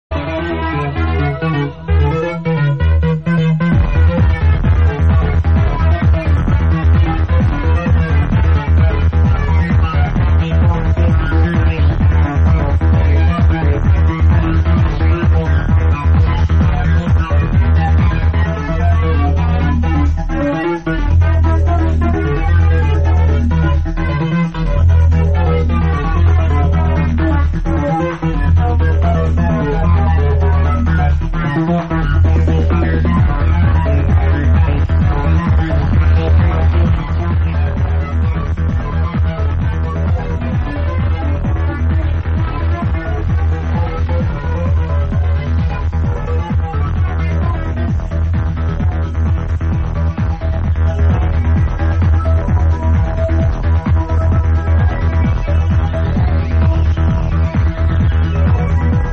tune from 1994...